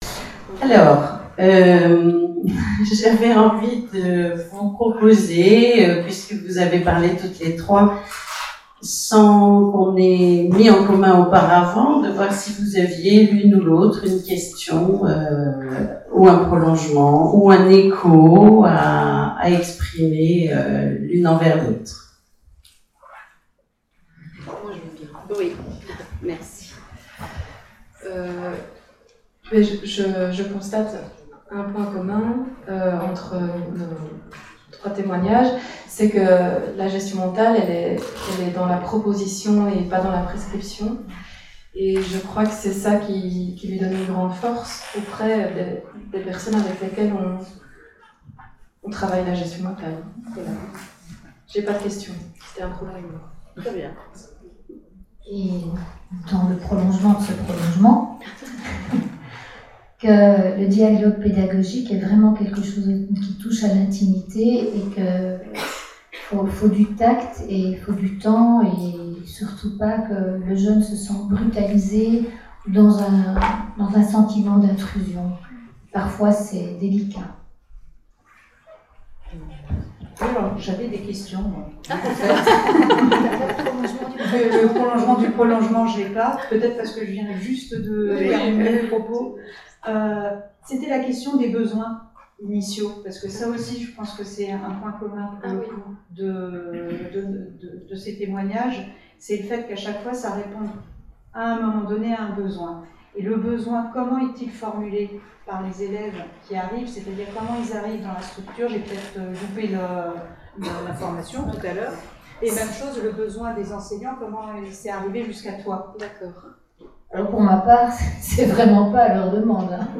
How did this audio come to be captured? Voici ci-dessous les versions audio des présentations courtes croisées de notre colloque de 2019, consacré à la liberté en éducation (le son y est meilleur que sur les vidéos).